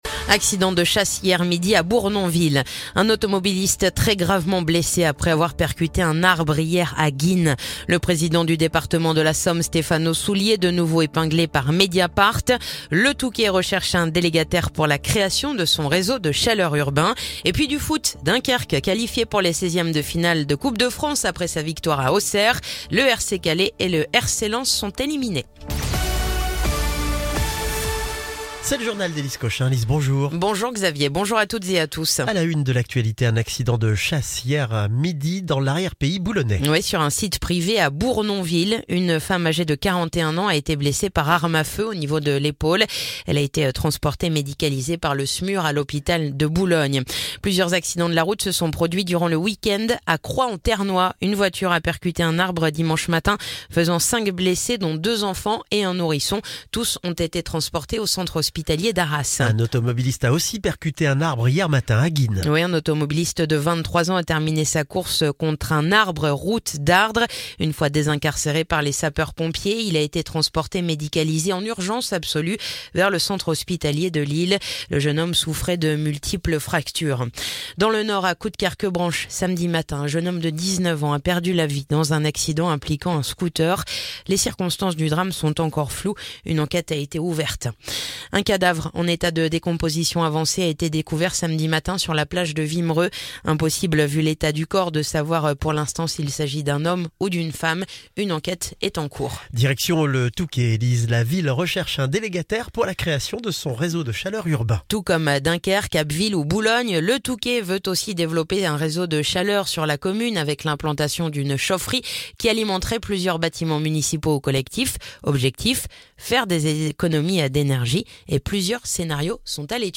Le journal du lundi 23 décembre